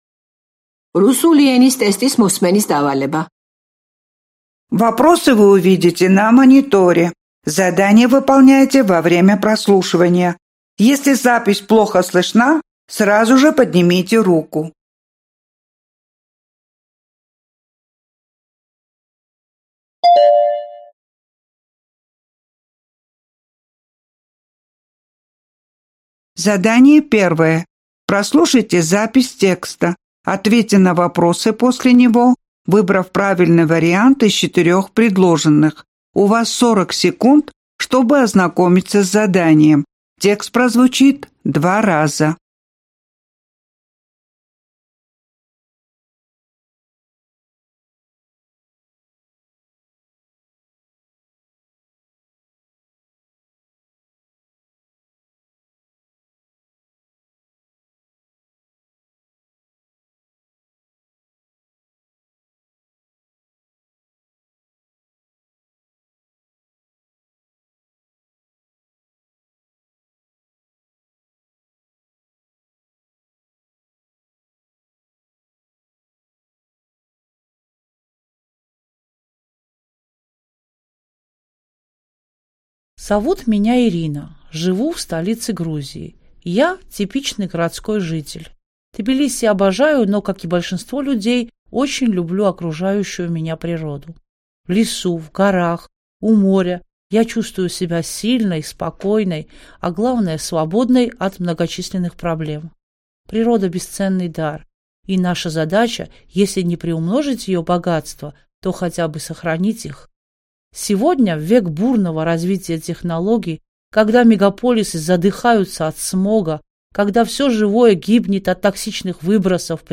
რუსული ენა - მოსმენის სავარჯიშოების ჩანაწერი